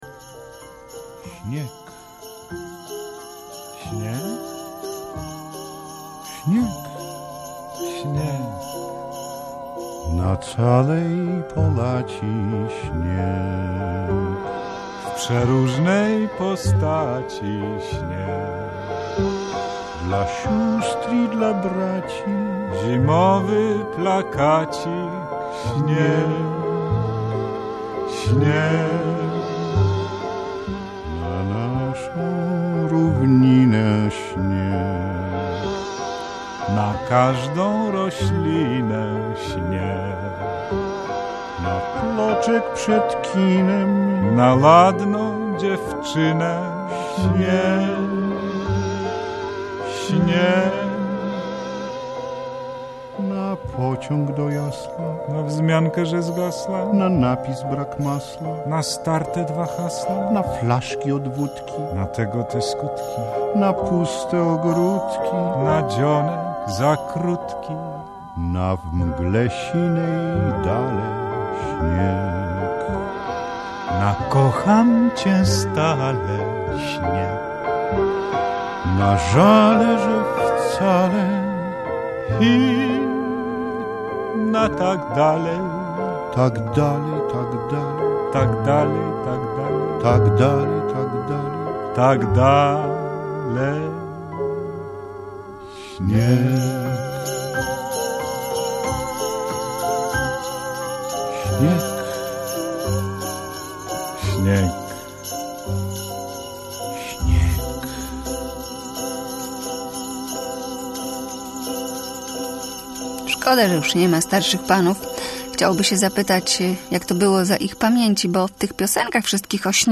Gdzie przepadła zima - reportaż